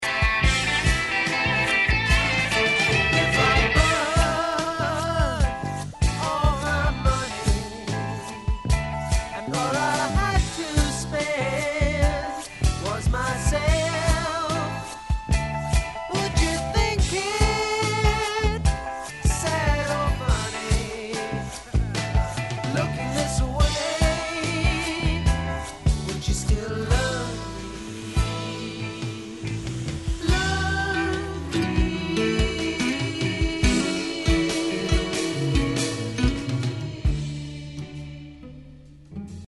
Recorded at Command Studios, London